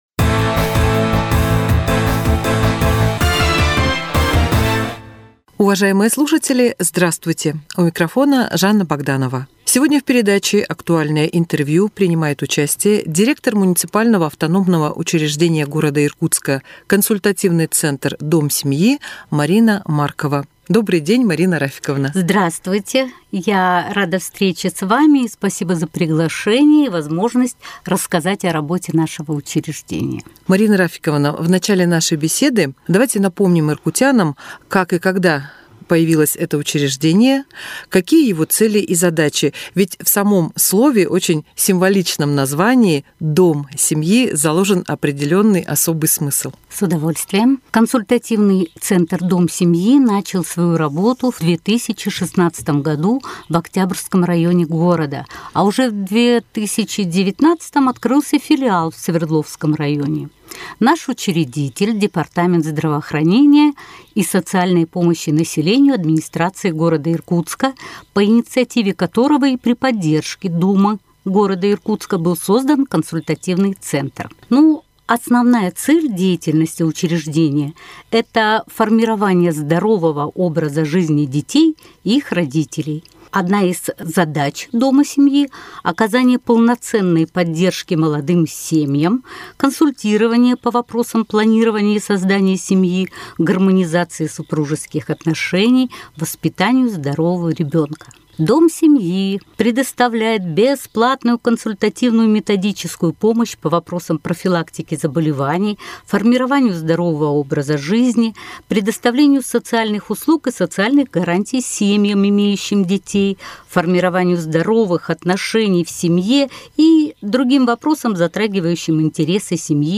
беседует в студии